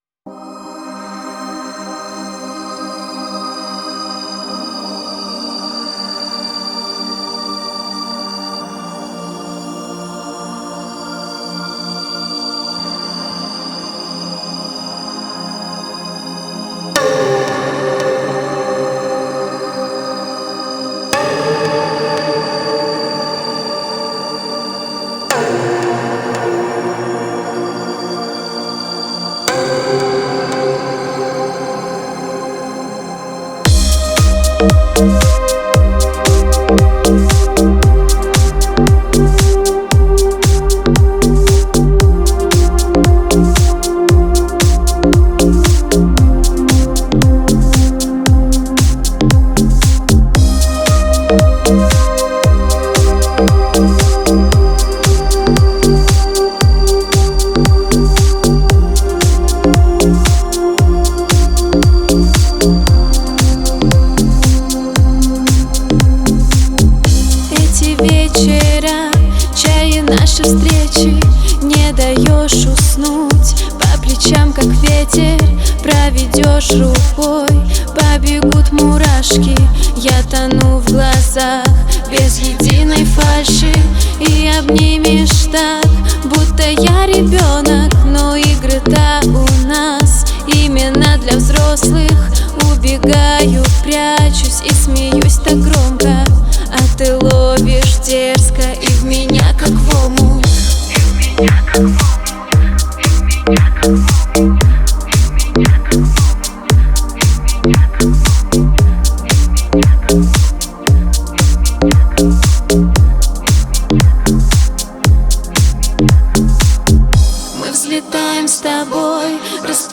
энергичная композиция в жанре поп-рок